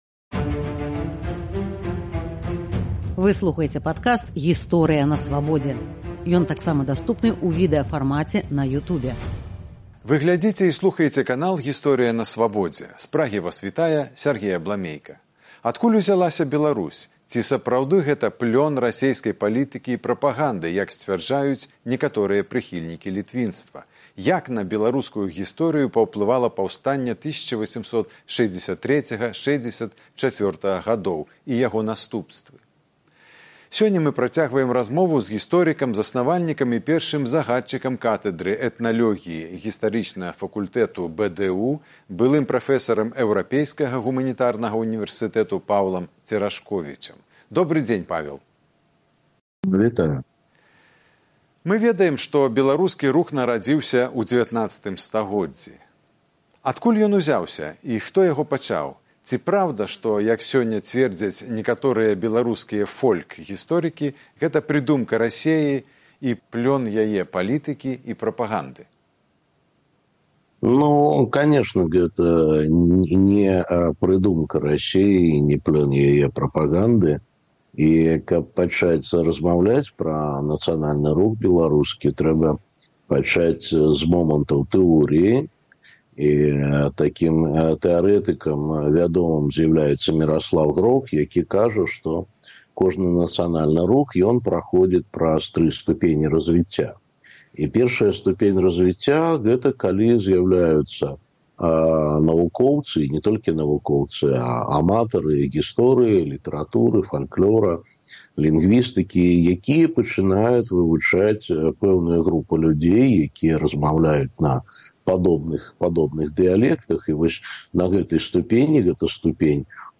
Адкуль ўзялася Беларусь? Ці сапраўды гэта плён расейскай палітыкі і прапаганды, як сьцьвярджаюць некаторыя прыхільнікі «літвінства»? Мы працягваем размову з гісторыкам